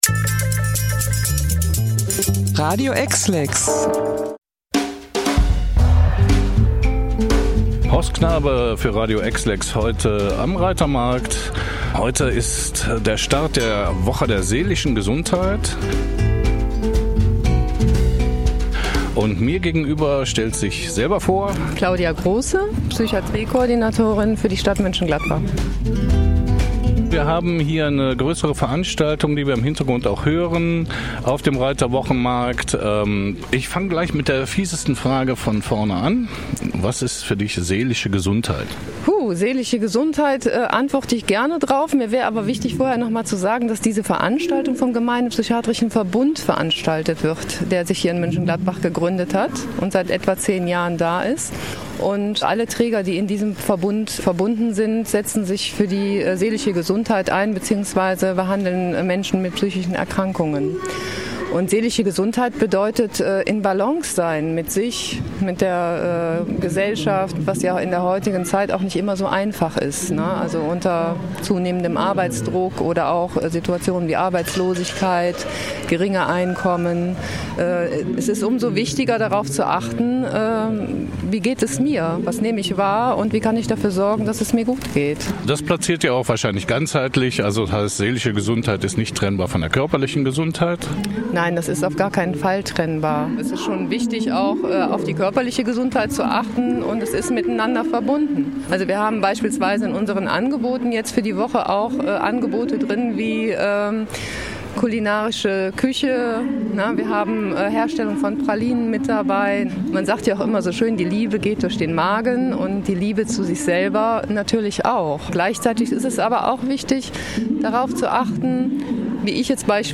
Interview-Seelische-Gesundheit-HK-TB.mp3